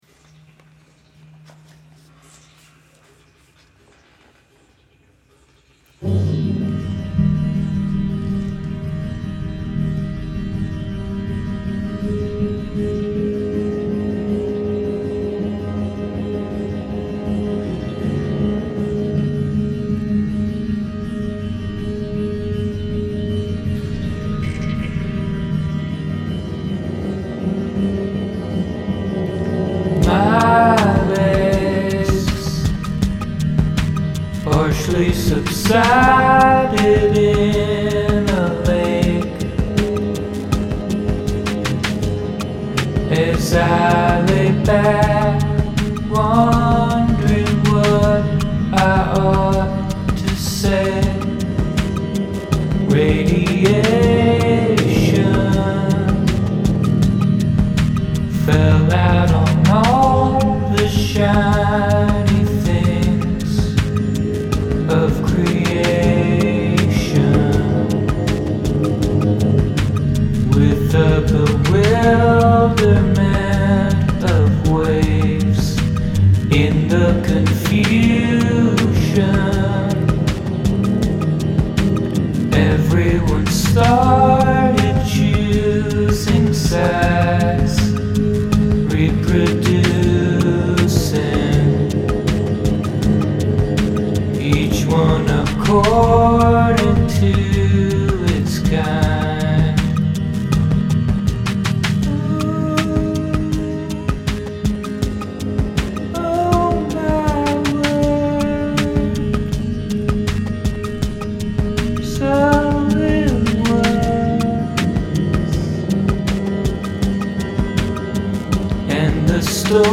C, Fmaj7, G7sus4
verse verse verse bridge half-verse
kind of a simple droney thing. i knew i wanted to try something with very fast strumming on some simple root position chords. i recorded the guitar first then put a bunch of delay and reverb on it. then i wrote the melody and words. i wrote the first line first then wasn't sure where to go with the song, and tried a bunch of stuff. the words came pretty quickly once i settled on doing a sort of creation story day dream. most of the lines look reasonably solid to me except for the "oh my word" bit. that line blows, but i'm too tired to think of anything better right now. i recorded a second guitar and put it through a ring modulator, which is the strange aahooogaaa thing. then i picked a drum beat and put it through a 4bit crusher.